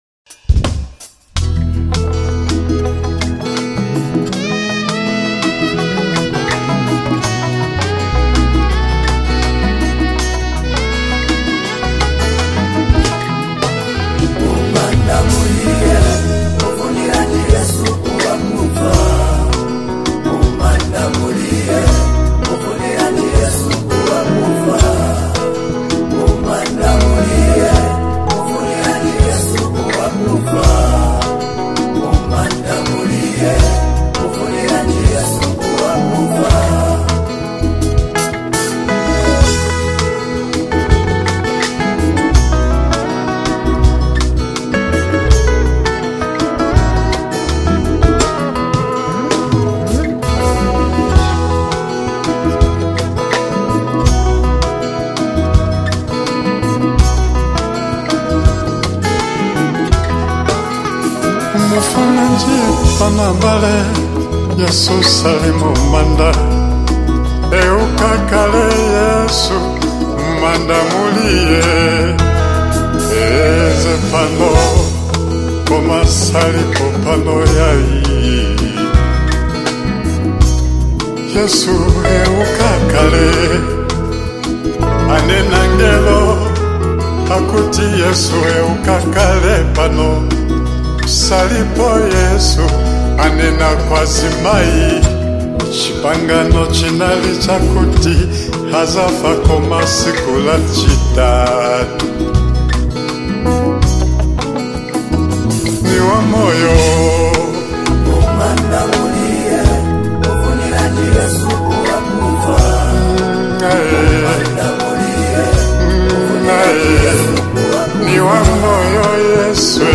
delivered with heartfelt passion and captivating melodies.
rich vocals
soulful Rumba rhythms